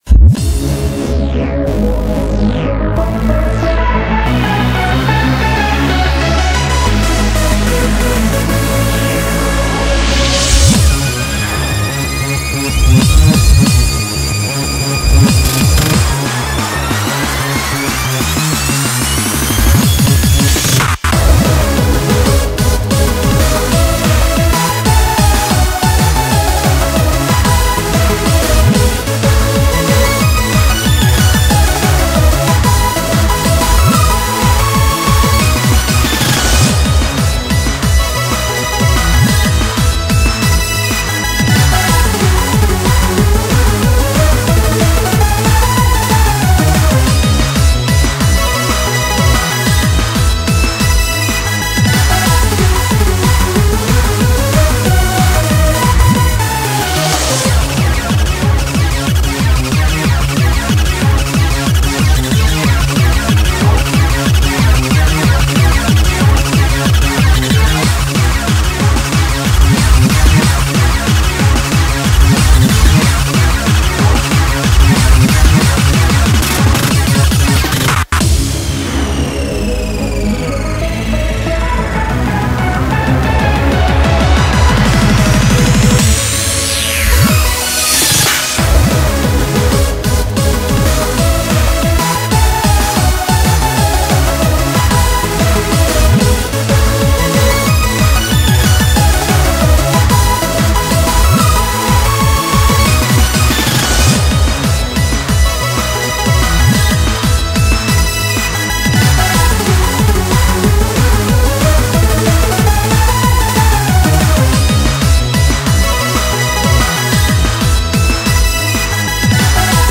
BPM185
Audio QualityCut From Video